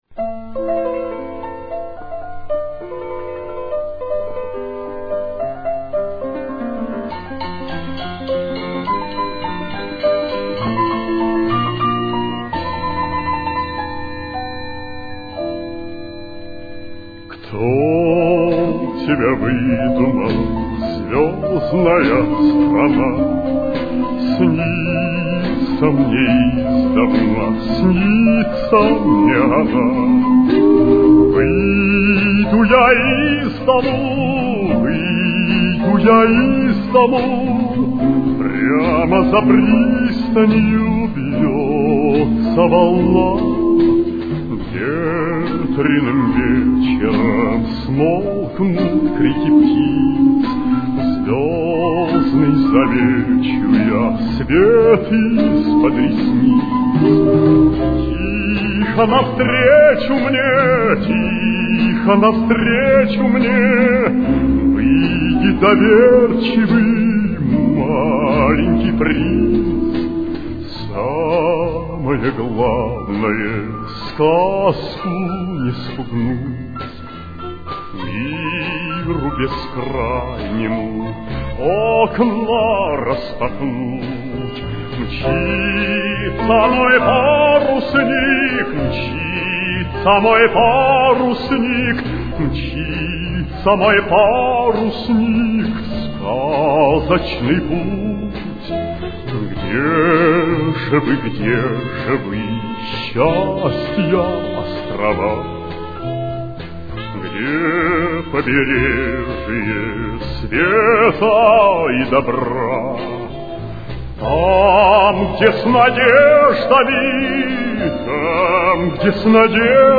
с очень низким качеством (16 – 32 кБит/с)
Ля минор. Темп: 76.